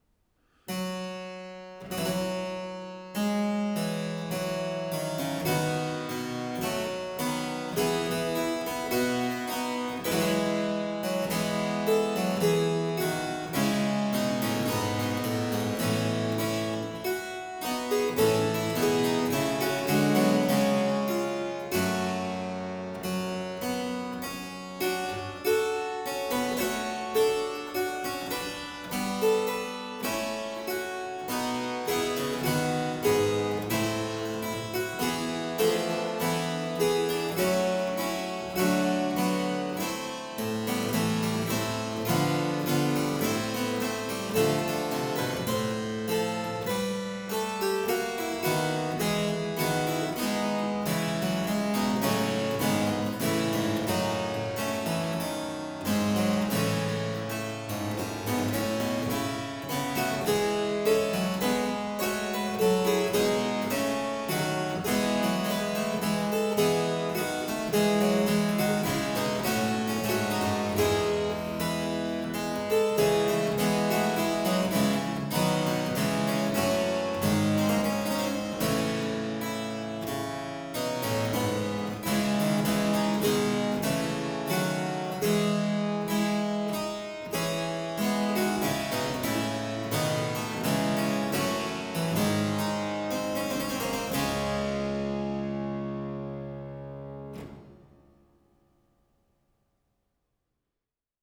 The text of the four-voice song was from the 5th Epistle of Horace, praising inebriation as a source of inspiration.